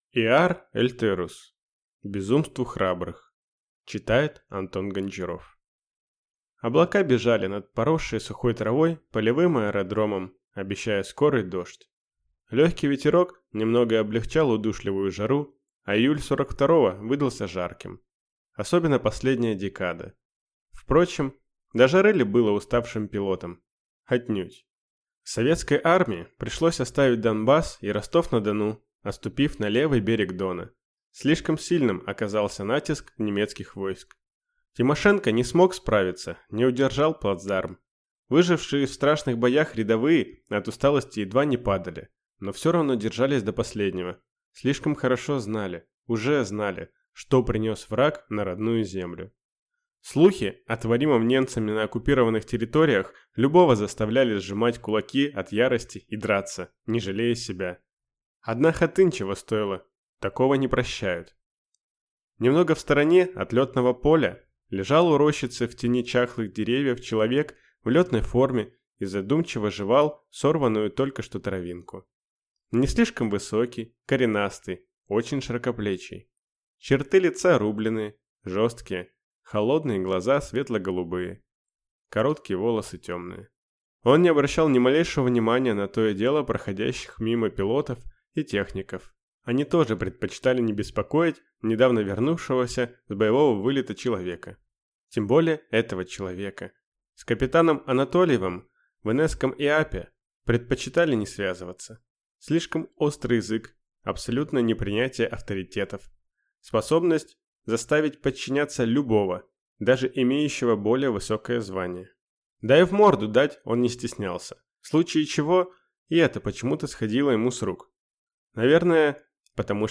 Аудиокнига Безумству храбрых | Библиотека аудиокниг